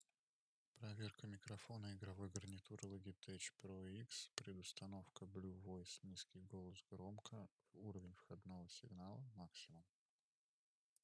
Голос звучит натурально, но «взрывные» (например, «п» и «т») и шипящие («с», «ф») звуки даются микрофону неидеально.
Тестовые записи
Пресет «Низкий голос – громко»: